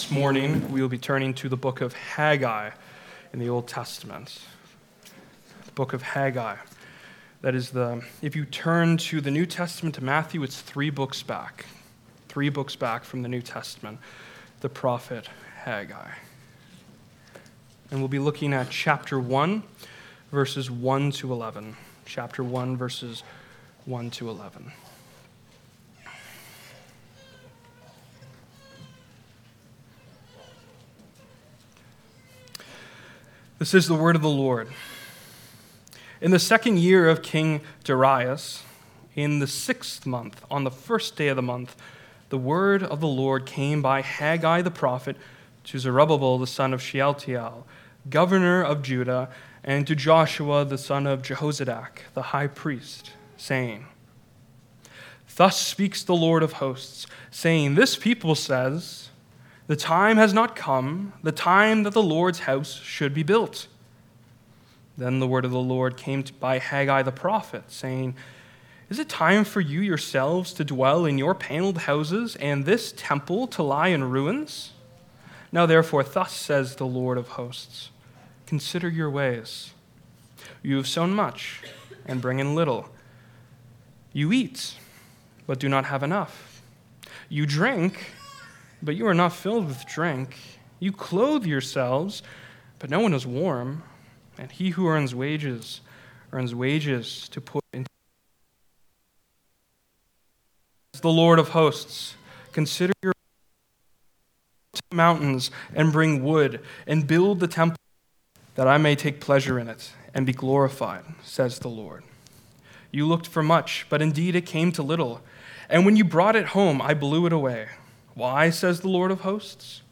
Passage: Haggai 1:1-11 Service Type: Sunday Morning